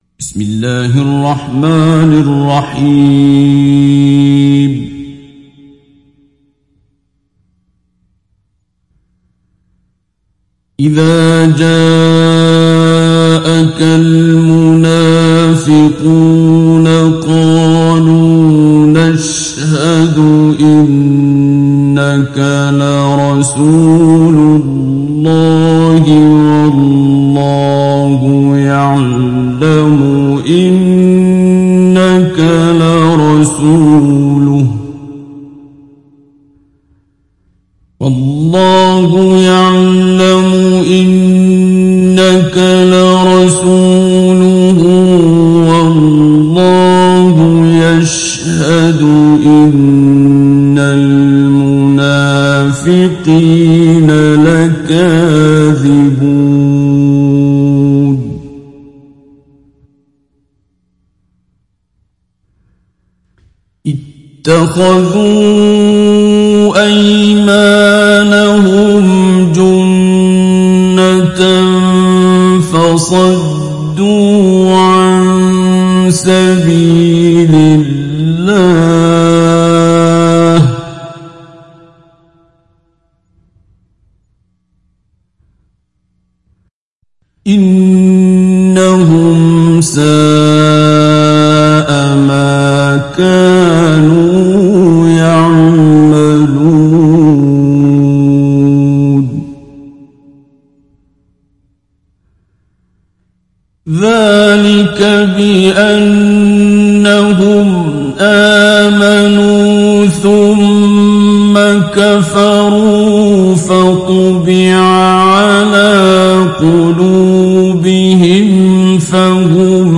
সূরা আল-মুনাফিক্বূন ডাউনলোড mp3 Abdul Basit Abd Alsamad Mujawwad উপন্যাস Hafs থেকে Asim, ডাউনলোড করুন এবং কুরআন শুনুন mp3 সম্পূর্ণ সরাসরি লিঙ্ক